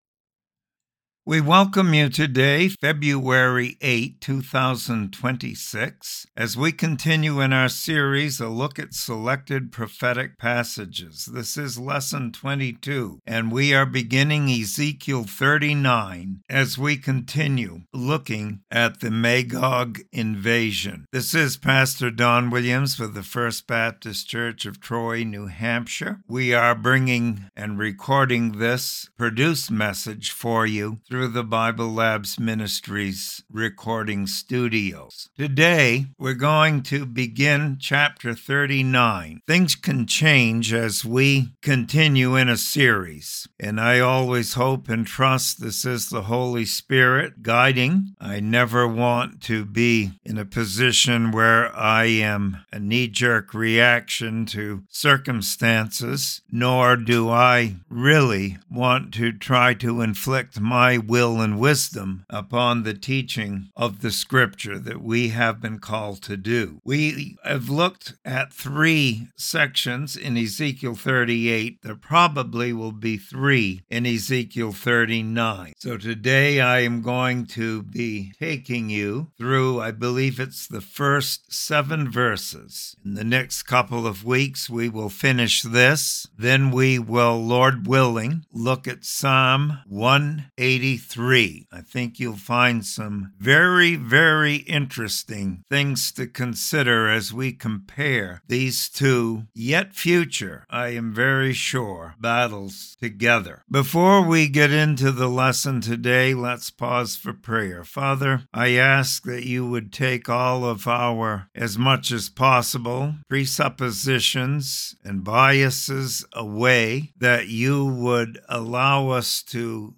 Sermon Library | First Baptist Church of Troy NH
Bible Study and Commentary on Ezekiel 39:1-7.